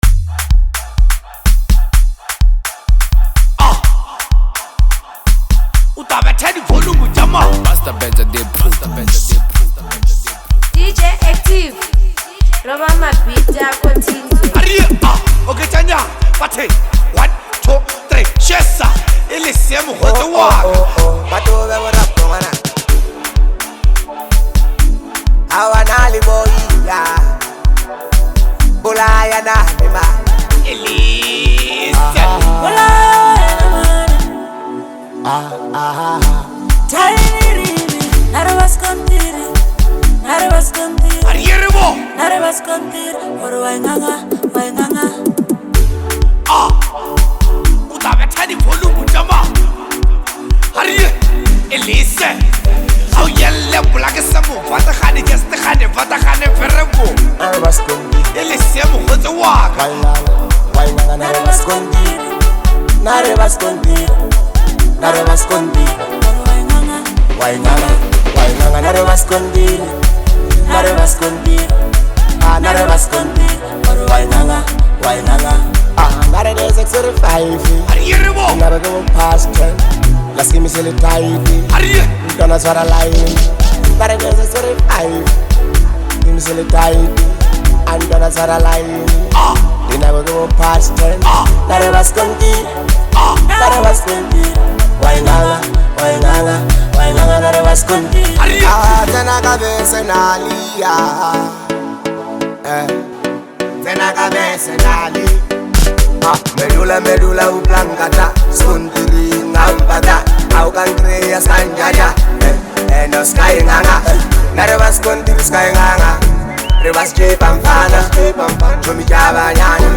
Lekompo